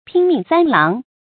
拼命三郎 注音： ㄆㄧㄣ ㄇㄧㄥˋ ㄙㄢ ㄌㄤˊ 讀音讀法： 意思解釋： 梁山好漢石秀的綽號。